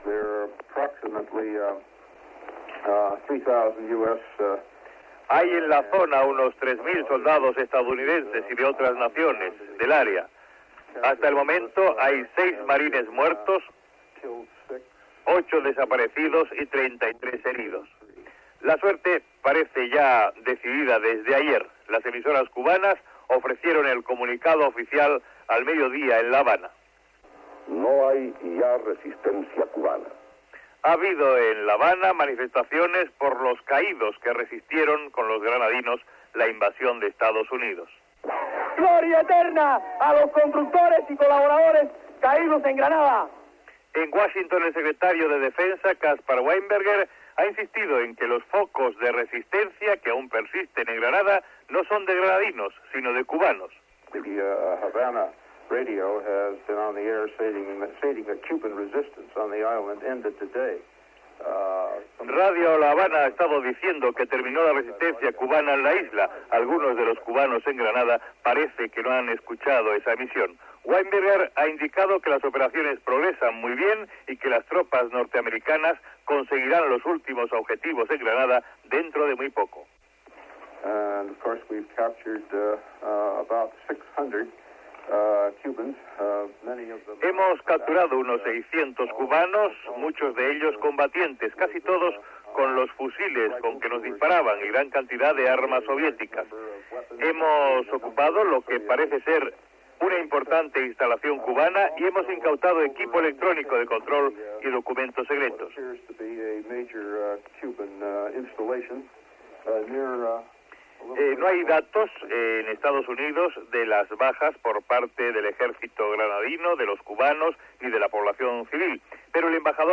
Informatiu
Extret del programa "El sonido de la historia", emès per Radio 5 Todo Noticias el 27 d'octubre de 2012